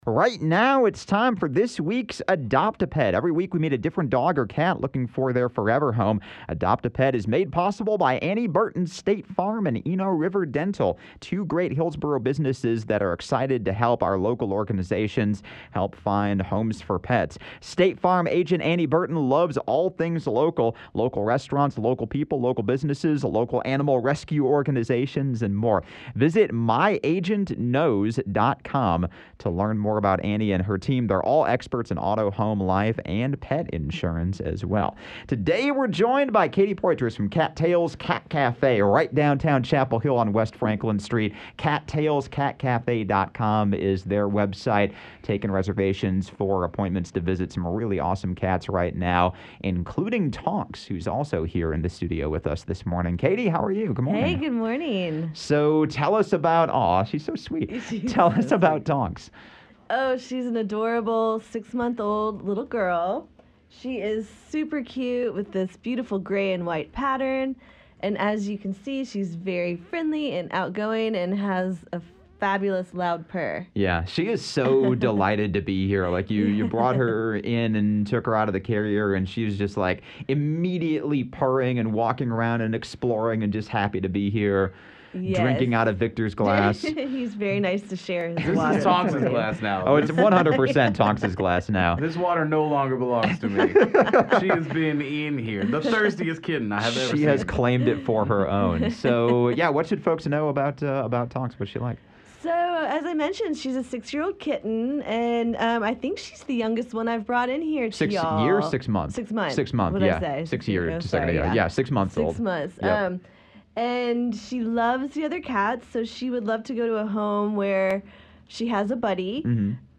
She was happy to explore the 97.9 The Hill studio and get as many pets as possible.
You can even hear her purring on the microphone, every now and then!